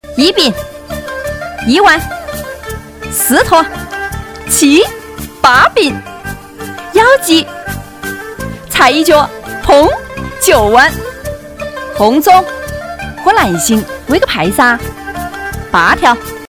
安徽方言女9号